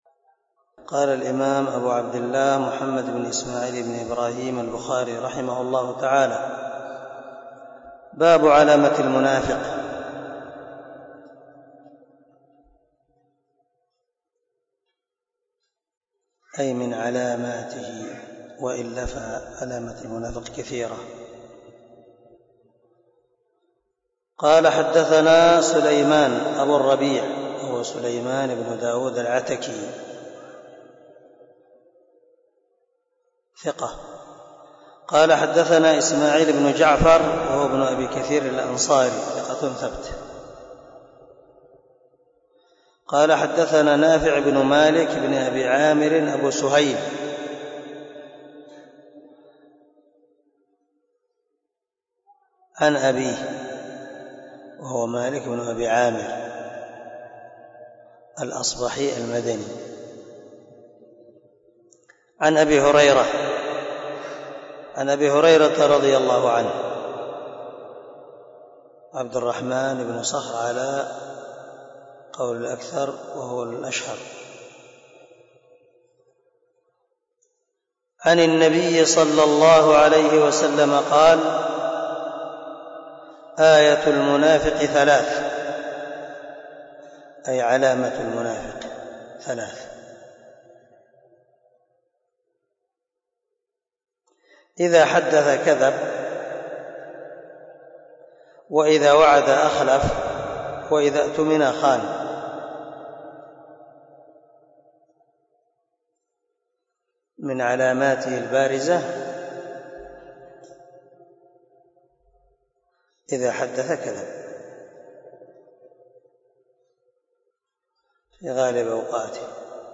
سلسلة_الدروس_العلمية
دار الحديث- المَحاوِلة- الصبي